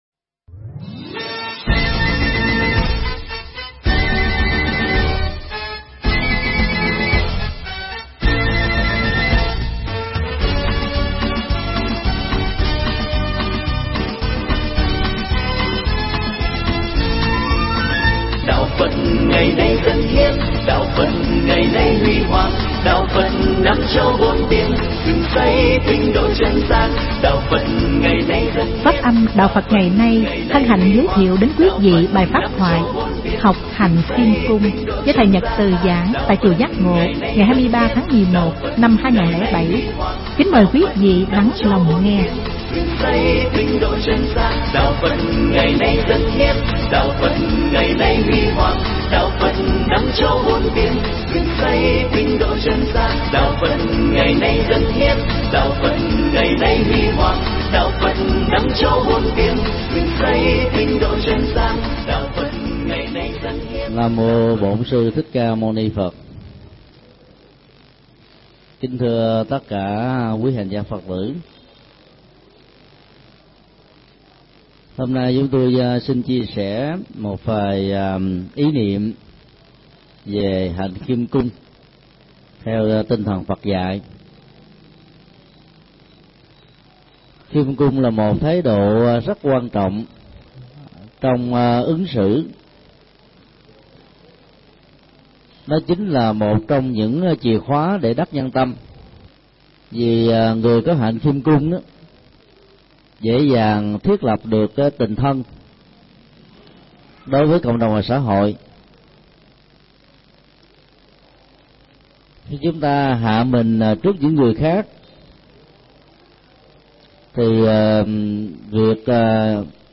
pháp thoại Học hạnh khiêm cung
giảng tại Chùa Giác Ngộ